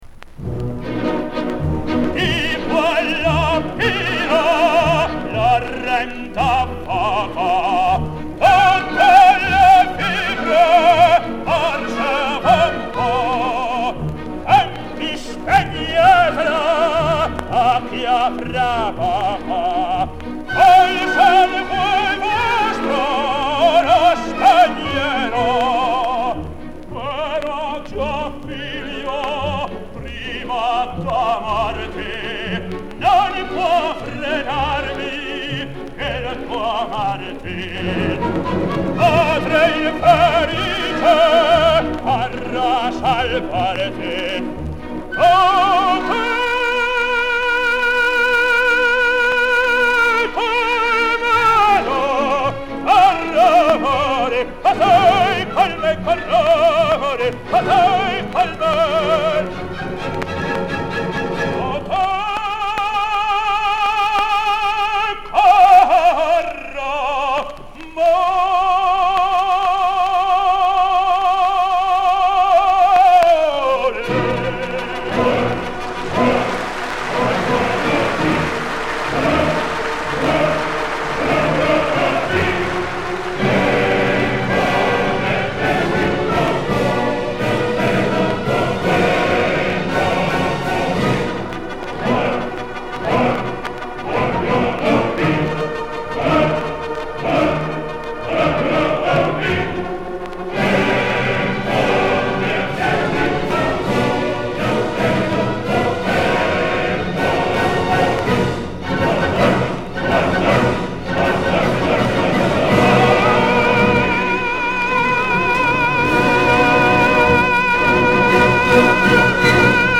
Сегодня исполняется 89 лет легендарному итальянскому тенору Карло Бергонци!!!
К.Бергонци-Стретта Манрико-Трубадур-Д.Верди (Москва, 1964г)